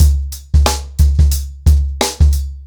TheStakeHouse-90BPM.11.wav